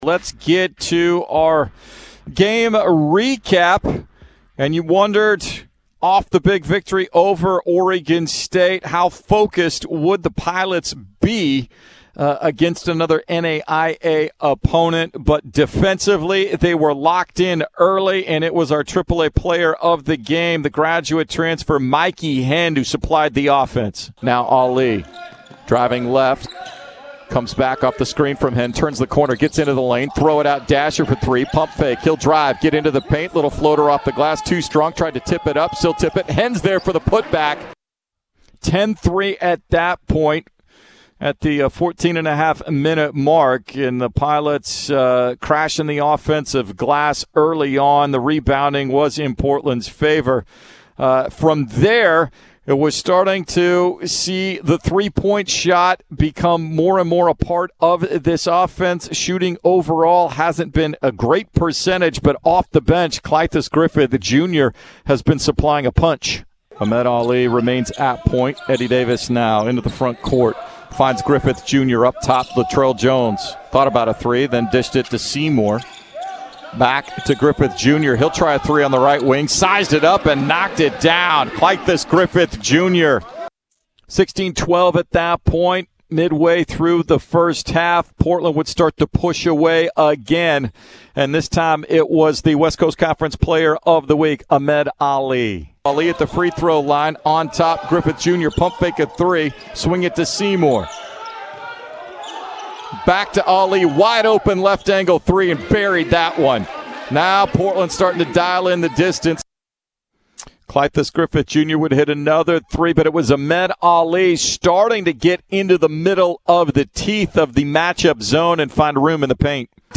December 15, 2020 Radio highlights from Portland's 88-74 win against the College of Idaho on Tuesday, Dec. 15 at the Chiles Center.